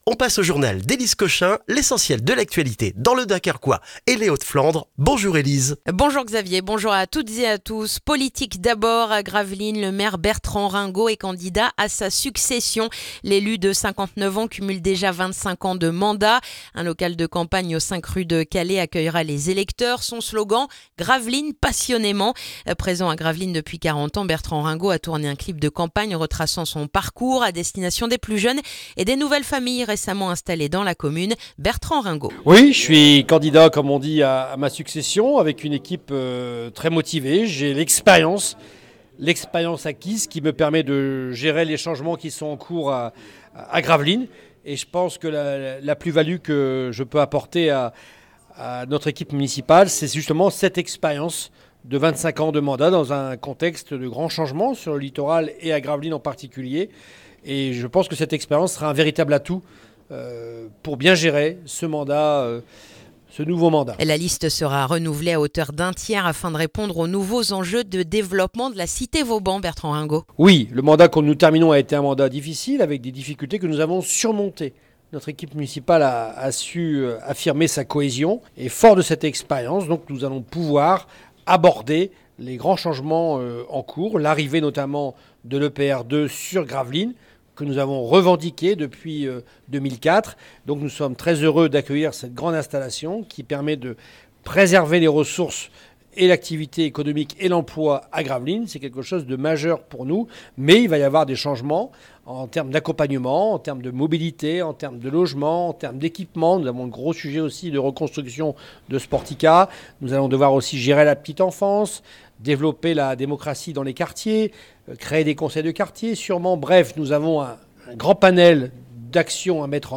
Le journal du mercredi 14 janvier dans le dunkerquois